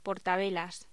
Locución: Portavelas
voz